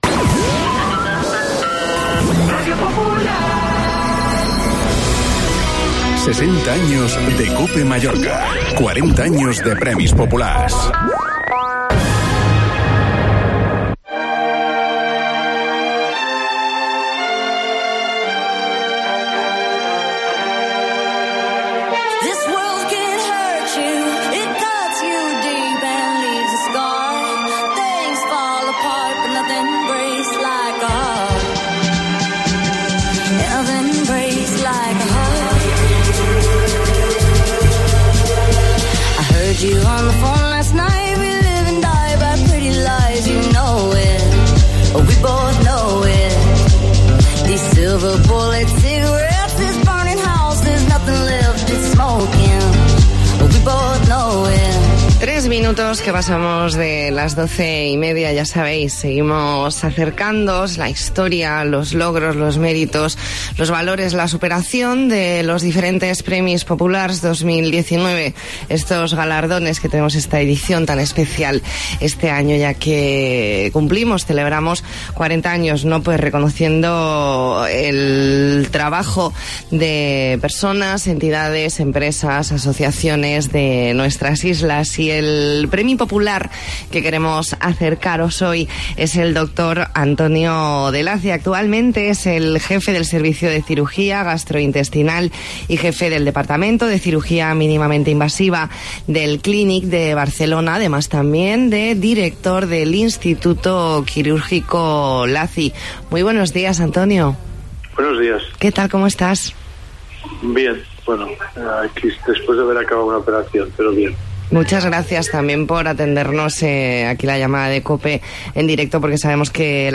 Hablamos con el doctor Antonio de Lacy, Premi Popular 2019, edición en la que se cumplen 40 años de estos galardones. Entrevista en La Mañana en COPE Más Mallorca, jueves 21 de noviembre de 2019.